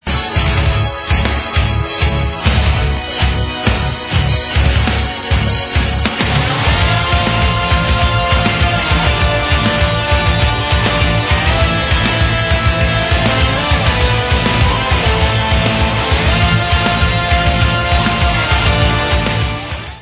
Rock/Progressive